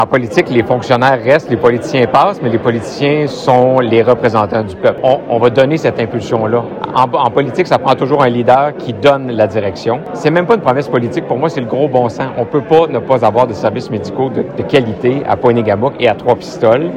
Charles Milliard s’est prononcé sur de nombreux sujets locaux dimanche lors d’une rassemblement militant à Saint-Alexandre-de-Kamouraska.
L’aspirant chef du Parti libéral du Québec a prononcé un discours devant un peu plus de 80 sympathisants et d’élus locaux.